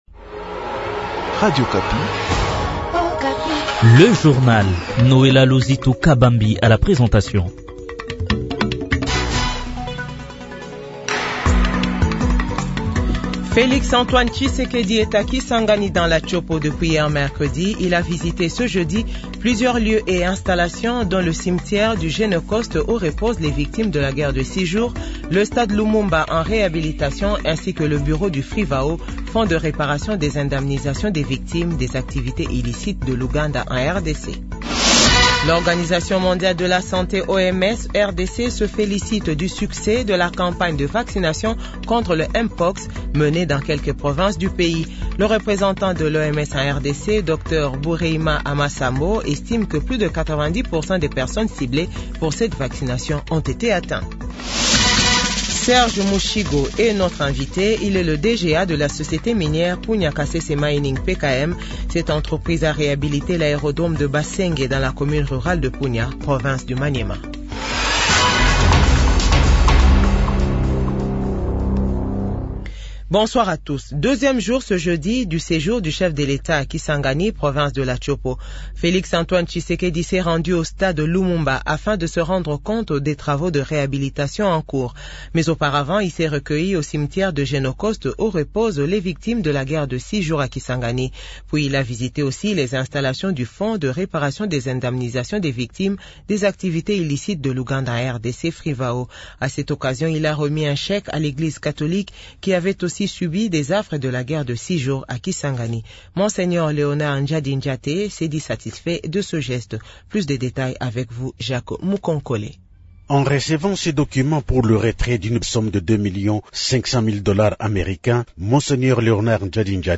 JOURNAL FRANÇAIS DE 18H00